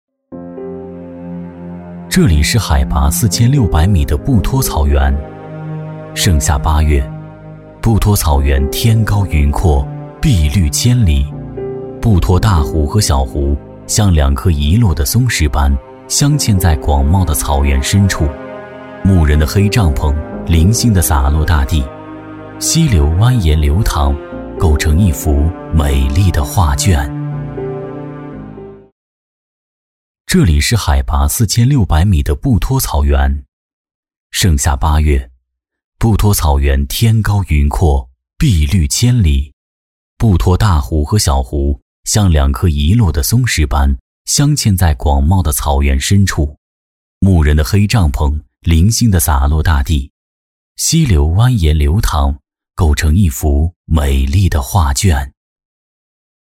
男B44-纪录片-叙述讲述感
男B44-明亮硬朗 质感磁性
男B44-纪录片-叙述讲述感.mp3